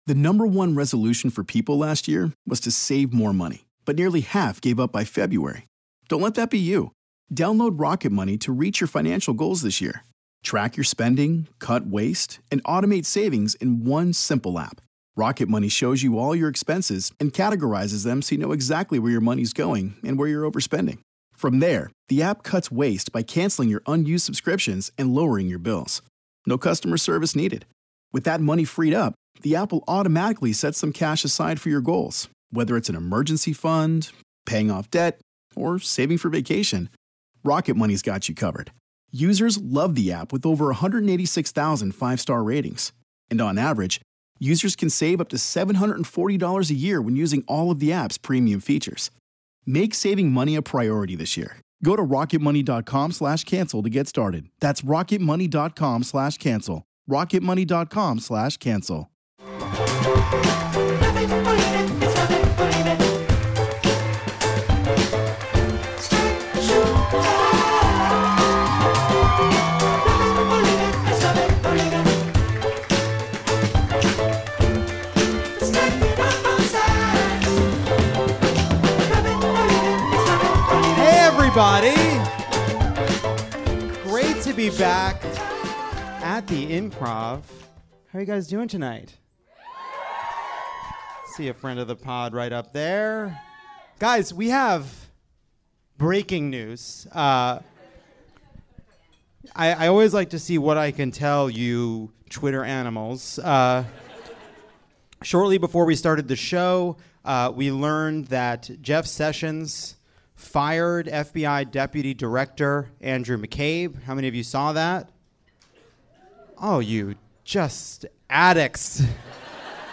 And Westworld’s Shannon Woodward stops by to help us understand what’s gone wrong with Education Secretary Betsy DeVos.